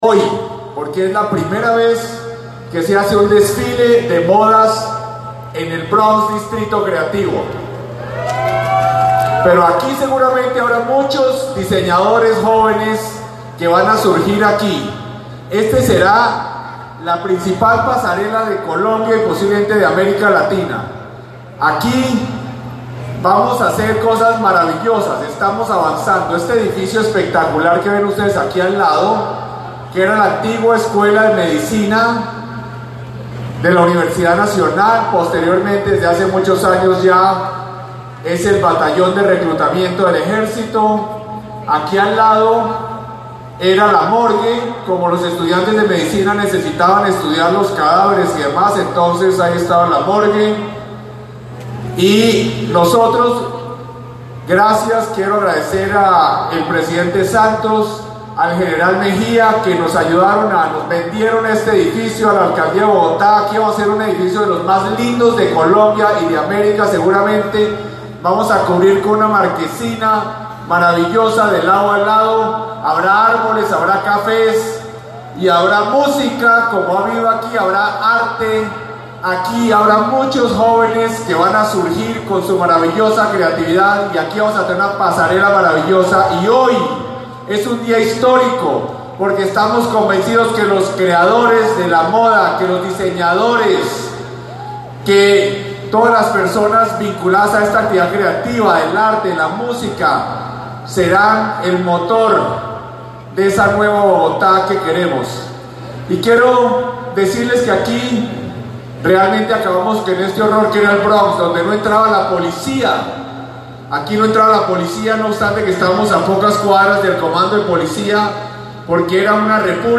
El pronunciamiento fue hecho en el marco del primer desfile de modas de las industrias culturales y creativas en el nuevo Bronx, realizado en el sector conocido como La Milla.
Audio_alcalde_Peñalosa_desfile_Bronx.mp3